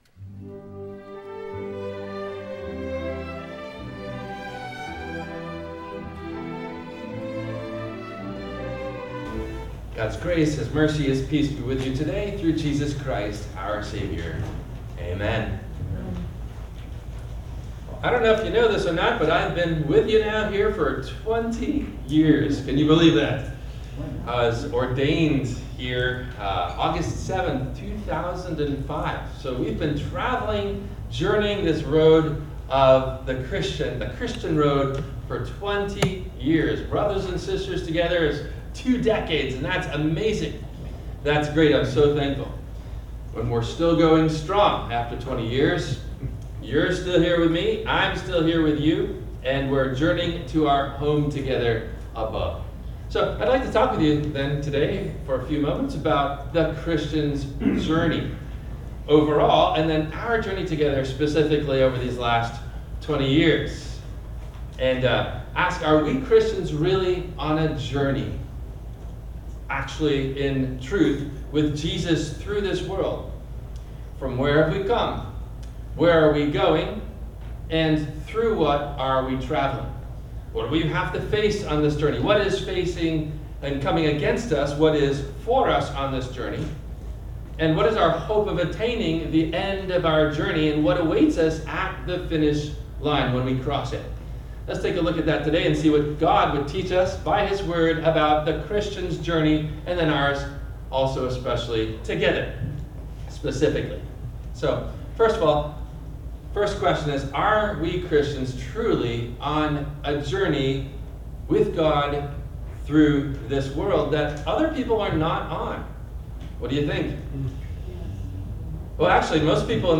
The Journey of the Christian – WMIE Radio Sermon – August 25 2025